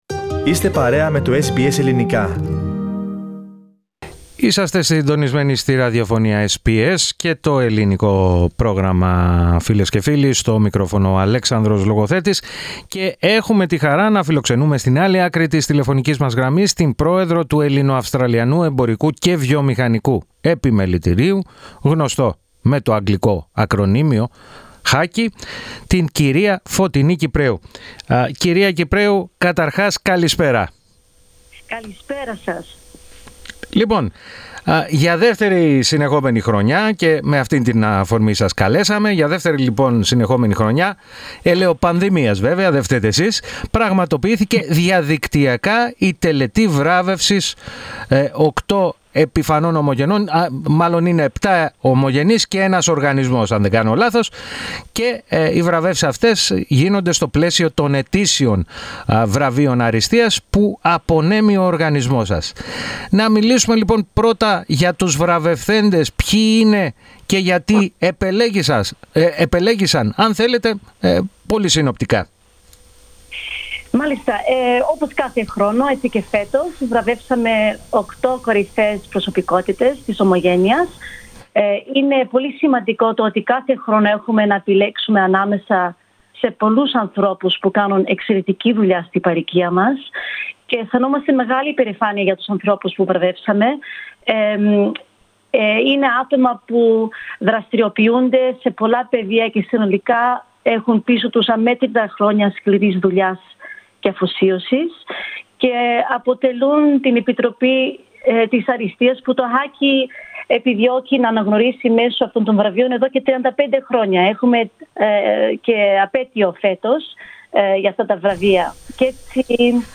μίλησε στο Ελληνικό Πρόγραμμα της ραδιοφωνίας SBS, με αφορμή τη χθεσινοβραδινή απονομή των βραβείων αριστείας του οργανισμού.